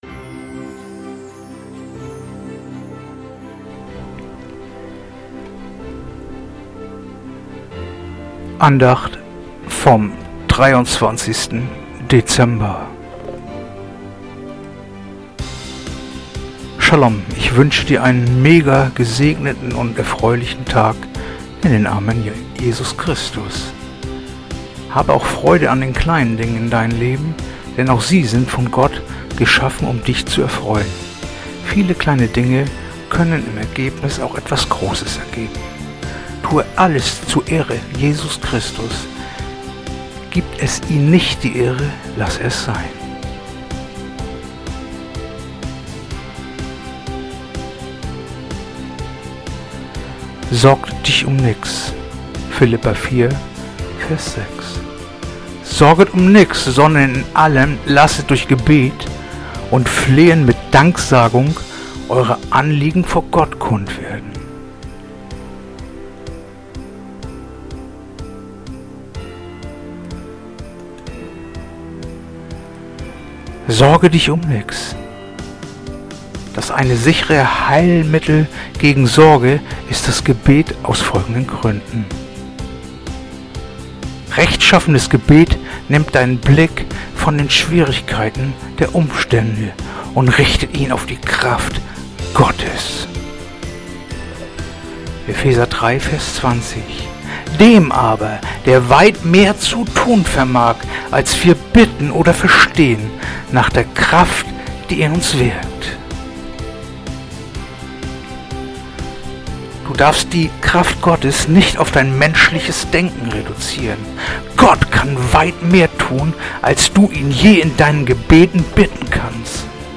Andacht-vom-23-Dezember-Philipper-4-6.mp3